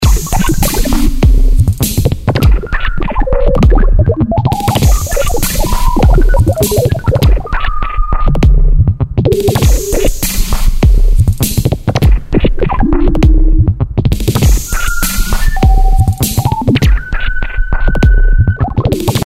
Hier steuert die Bassdrum aus Engine 1 den Modulator Mini Sequencer in Engine 2. Dieser bewirkt dort ein Time-Stretching des Bleep-Sounds, was diesen für kurze Zeit in eine Art Rauschen transformiert.